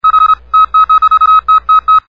MorseCode